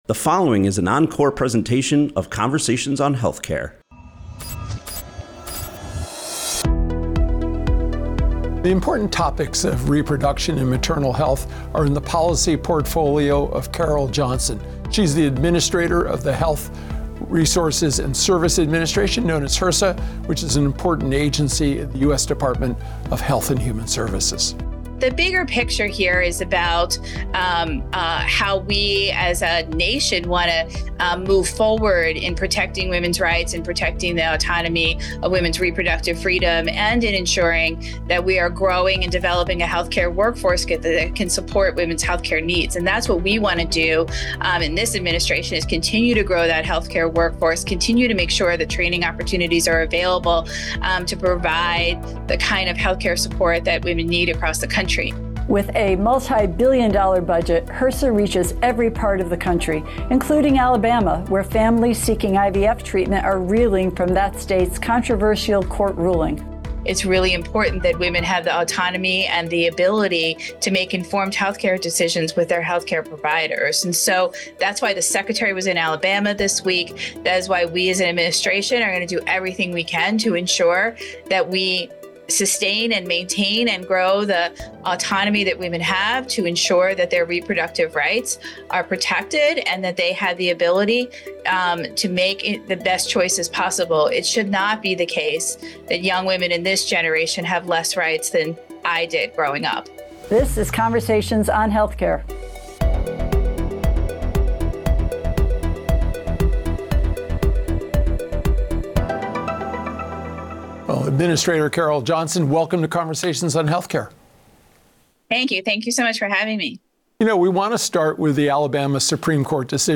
As we near election day, we’re looking back to an interview with a national leader on these issues. Carole Johnson is the administrator of the Health Resources and Services Administration, which is part of the U.S. Health and Human Services Department.